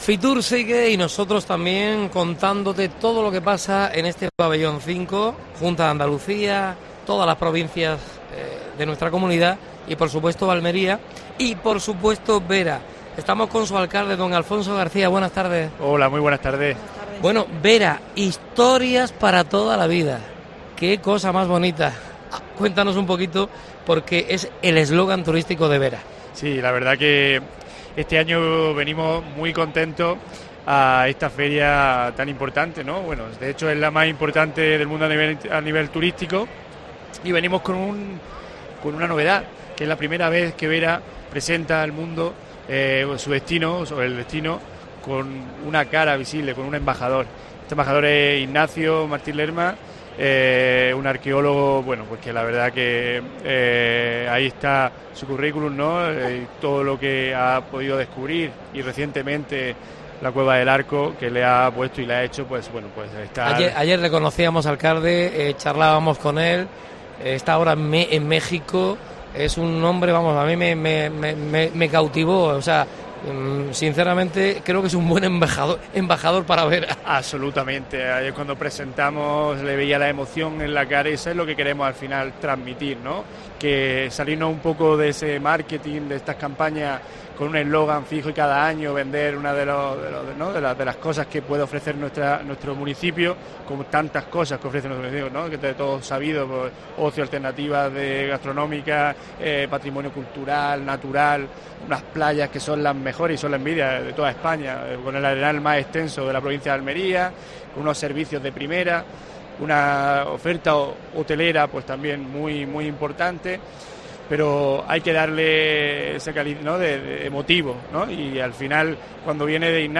AUDIO: Especial FITUR en COPE Almería. Entrevista a Alfonso Vera (alcalde de Vera).